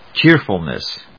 /ˈtʃɪrfʌlnʌs(米国英語)/